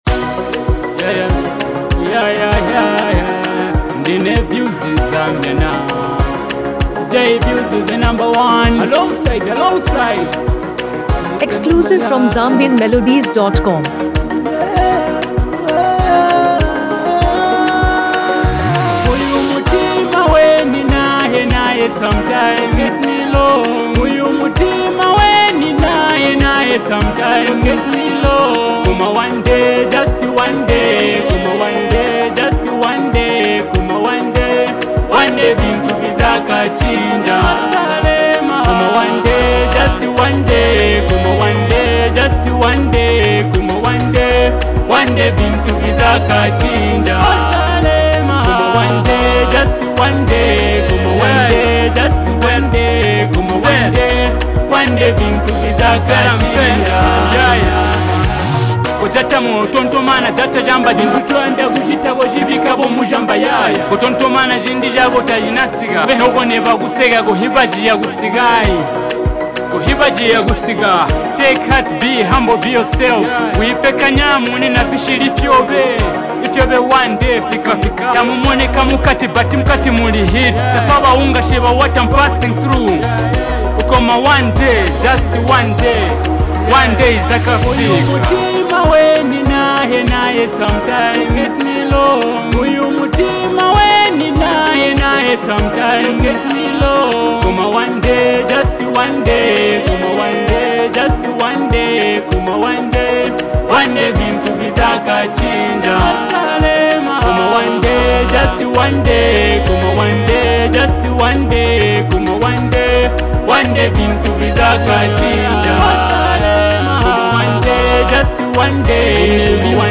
a deeply emotional Zambian love song